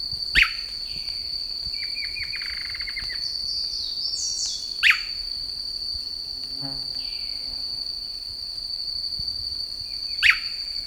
SWALLOW-TAILED MANAKIN Chiroxiphia caudata